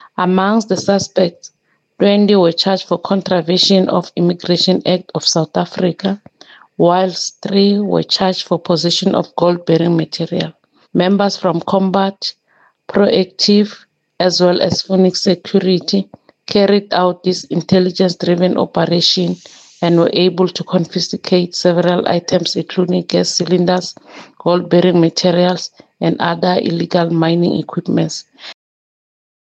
‘n Woordvoerder